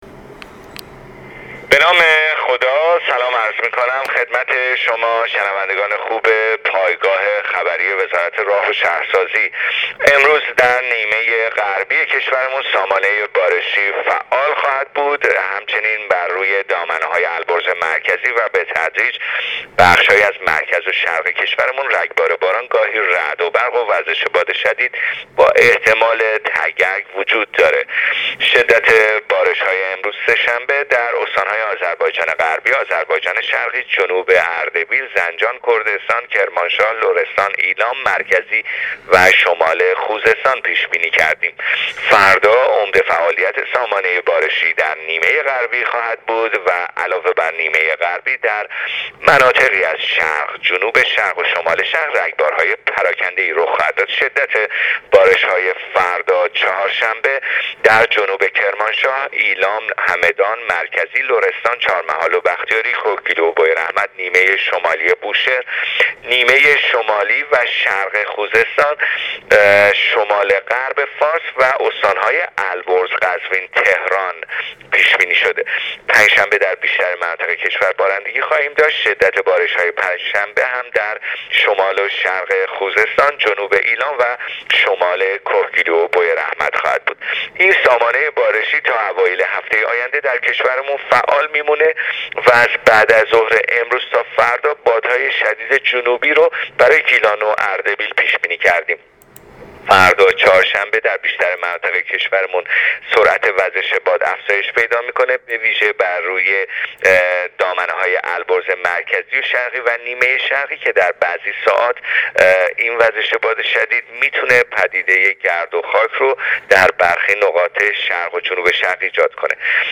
کارشناس سازمان هواشناسی در گفتگو با راديو اينترنتی پايگاه خبری آخرين وضعيت هوا را تشریح کرد.